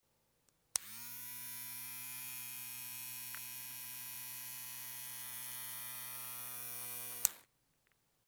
Звуки бритвы
Шум триммера или жужжание машинки для стрижки